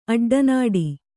♪ aḍḍanāḍi